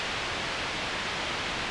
normal-sliderwhistle.ogg